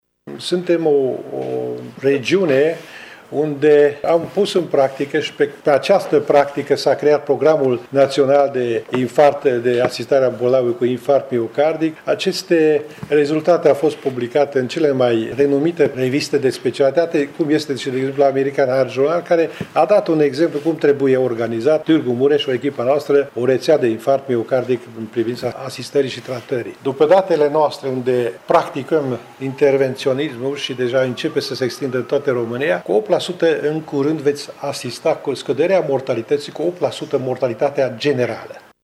Acesta a declarat, azi, într-o conferinţă de presă că prin implementarea unei reţele de asistare a bolnavului care a suferit un infarct, dar şi a tehnicilor moderne de tratament, rata mortalităţii a scăzut de la 16 la 8 %: